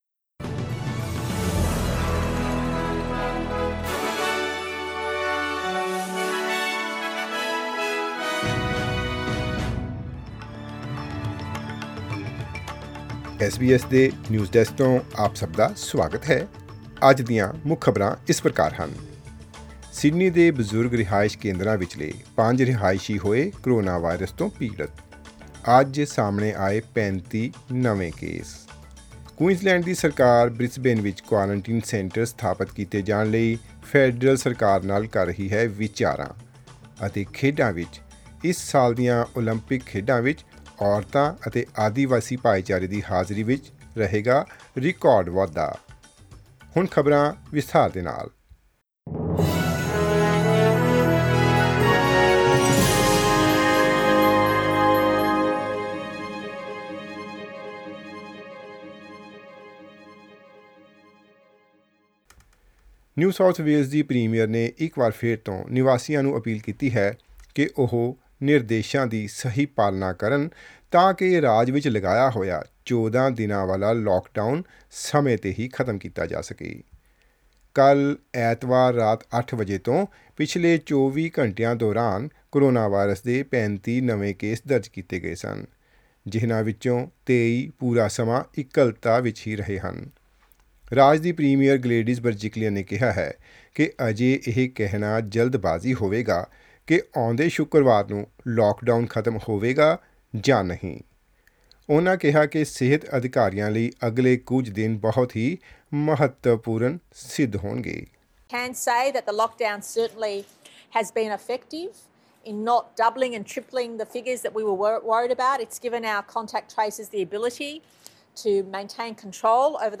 Listen to the latest news headlines in Australia from SBS Punjabi radio.
Click on the player at the top of the page to listen to the news bulletin in Punjabi.